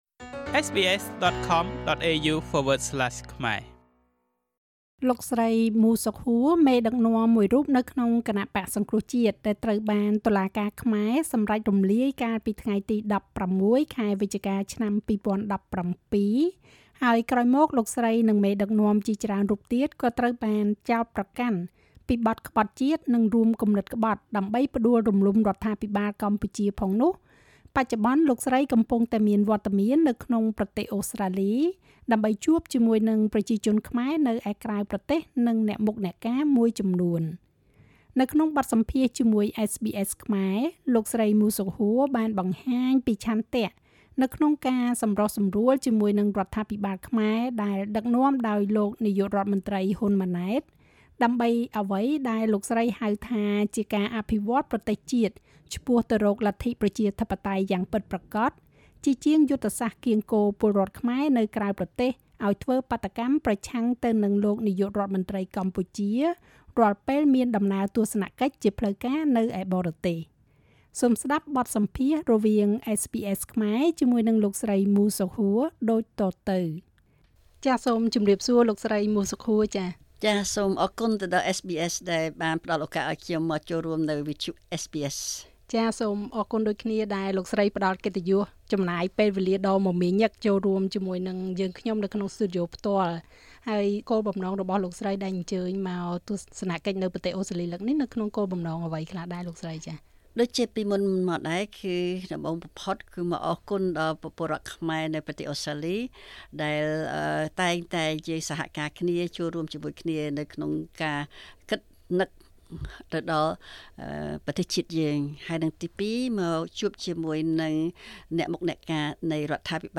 សូមស្តាប់បទសម្ភាសន៍រវាងSBSខ្មែរ និងលោកស្រីមូរ សុខហួរ ដូចតទៅ។